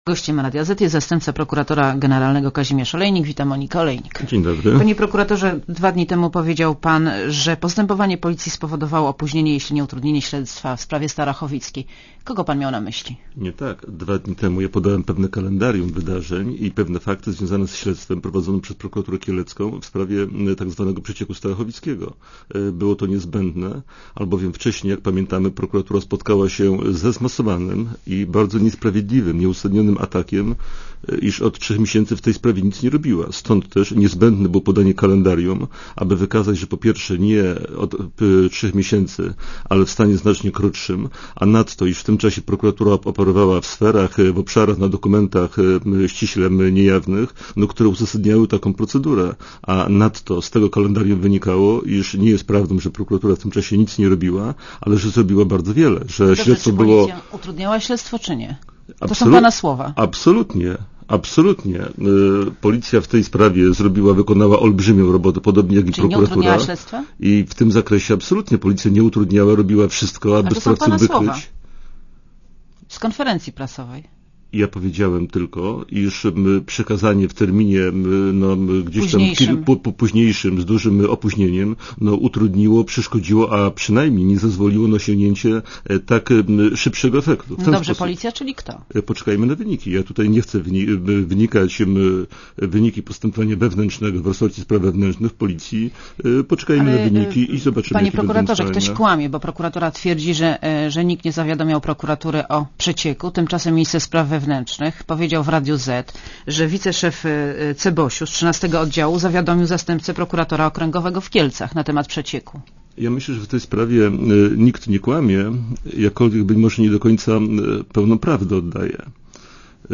Starachowic 26 marca - przyznał w czwartek w Radiu Zet zastępca